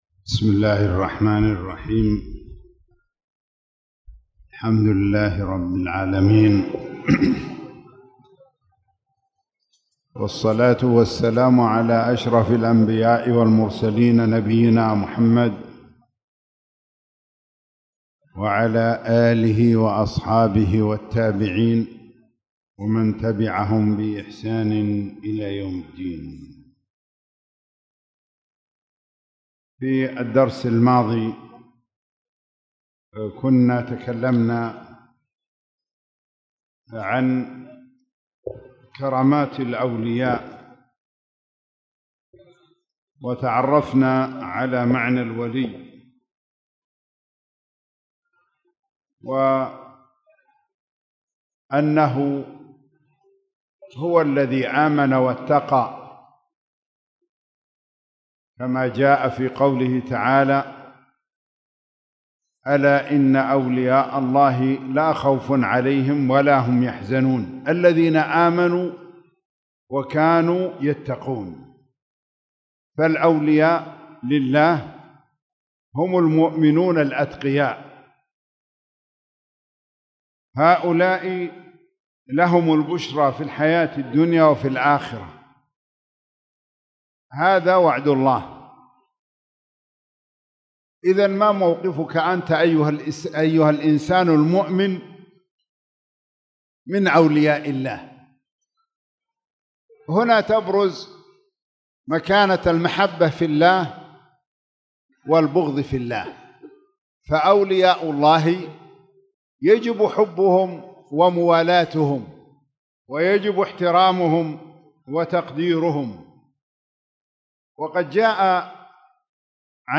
تاريخ النشر ١٨ ربيع الثاني ١٤٣٨ هـ المكان: المسجد الحرام الشيخ